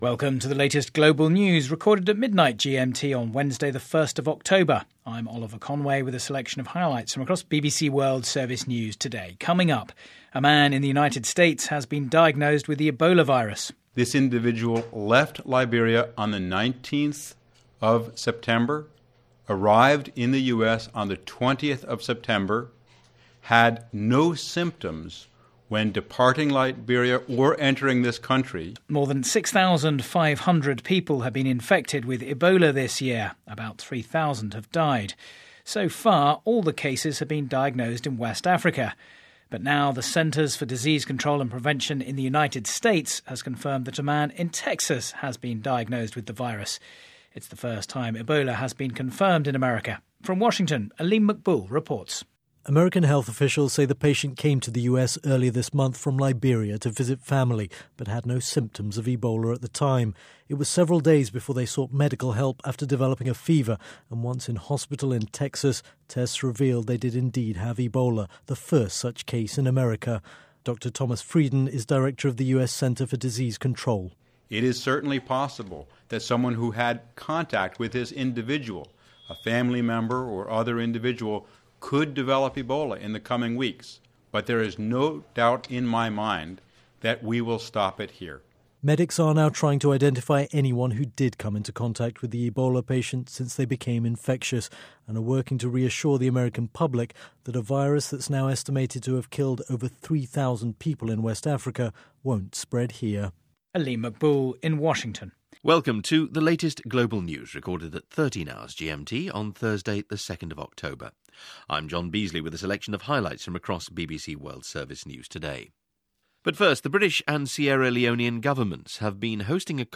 Newscasts: Ebola Virus outbreak – BBC World Service – CBS Radio News – October 1-17, 2014 –